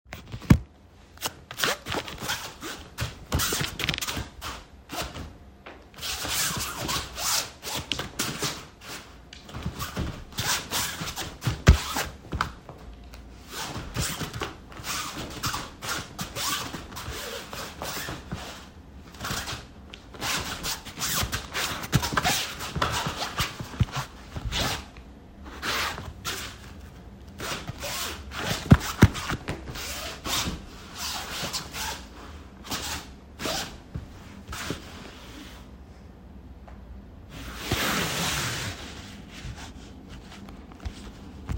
Feet on the floor
When I showed it to others, they said there is something about the sound my feet on the ground make.
en FeetTouchingTheGround
en Riga, Latvia